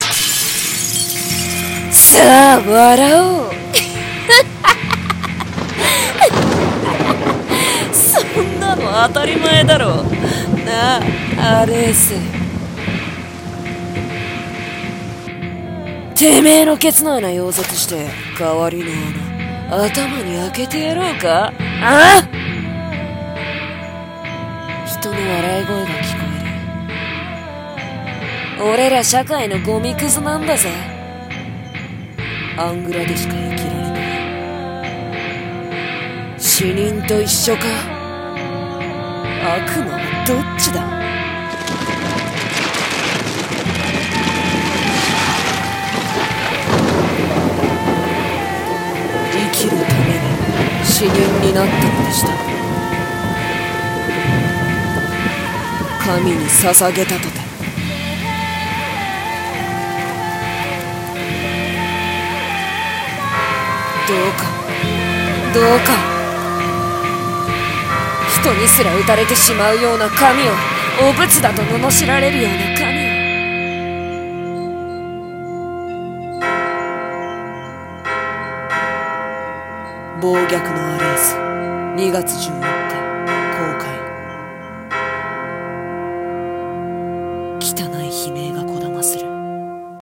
【予告風声劇】